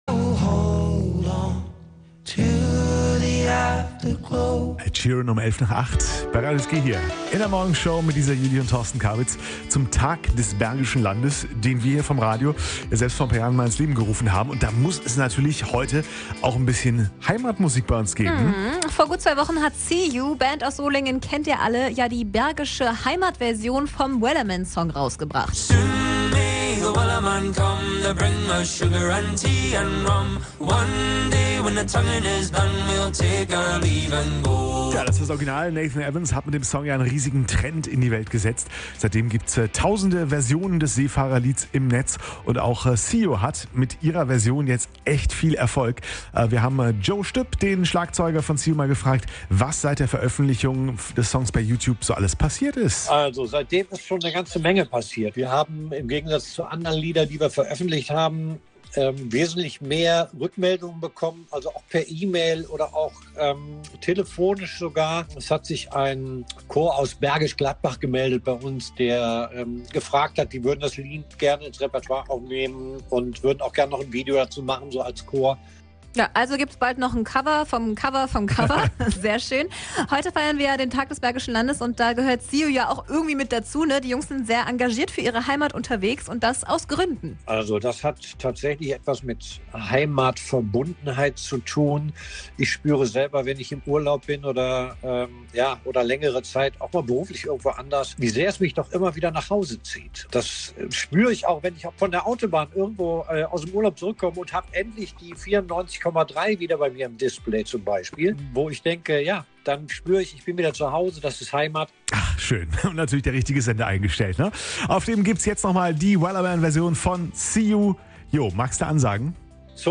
in der Shanty-Version